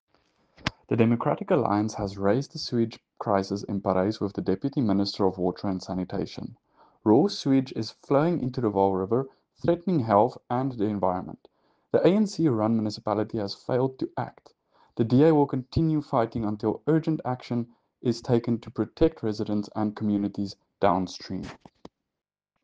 Afrikaans soundbites by Cllr JP de Villiers and
sewage-crisis-in-Parys-ENG-.mp3